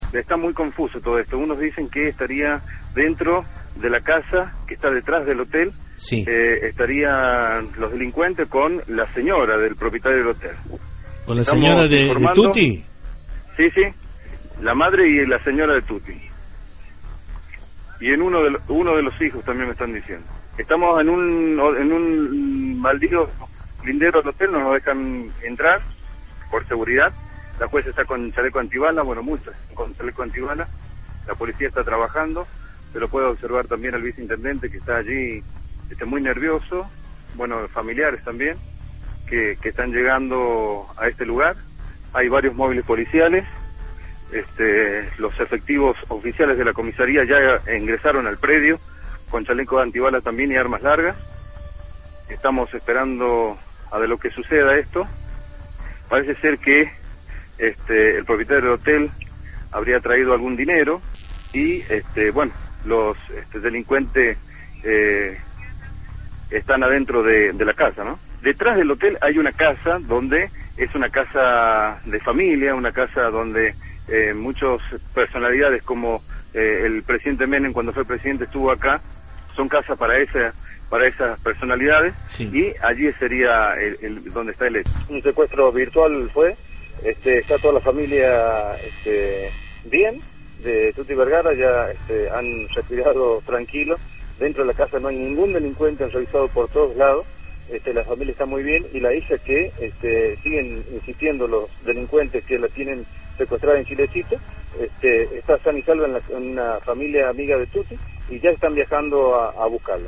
Informe de Radio Provincia